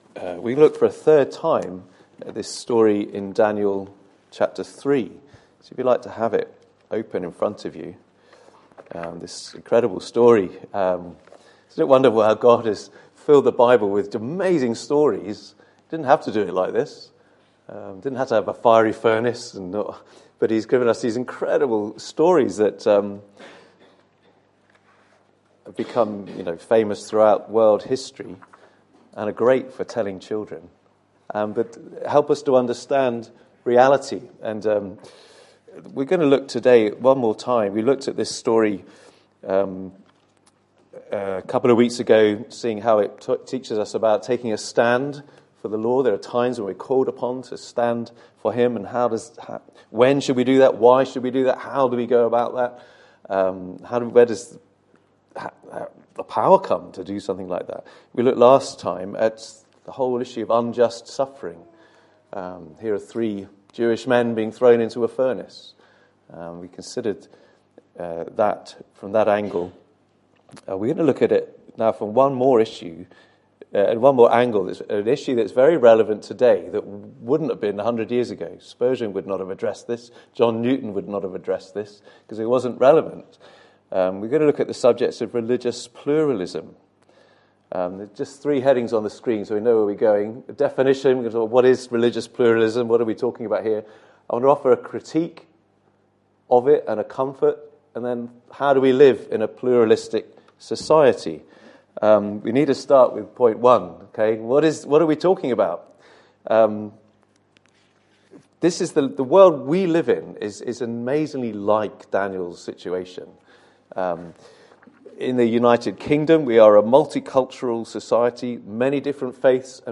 Book of Daniel Passage: Daniel 3:1-30, 1 Peter 2:13-25 Service Type: Sunday Morning « Jephthah